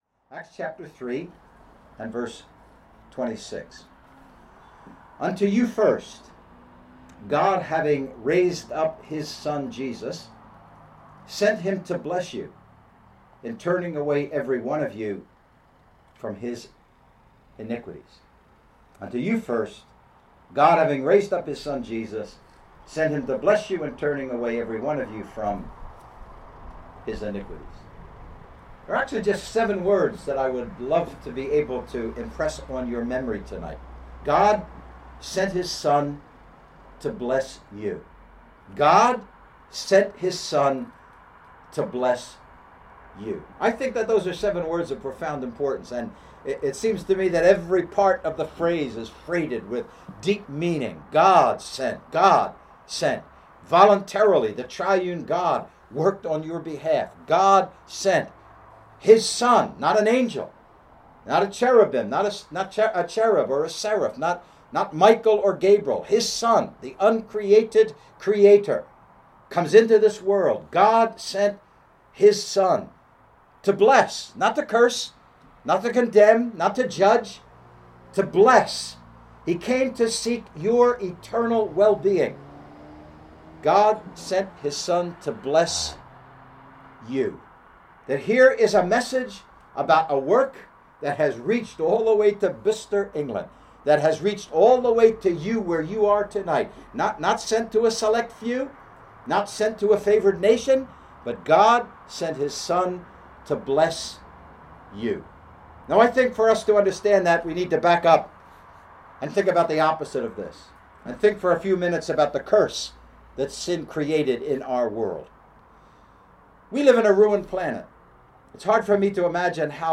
Gospel Meetings 2022